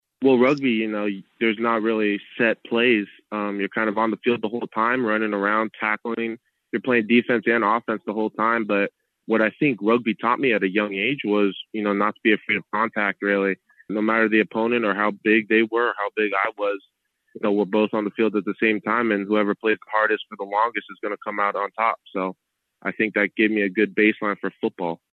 On a zoom call after joining the Packers, Rhyan said it doesn’t matter where the Packers line him up, he’s ready to compete and help the team win games.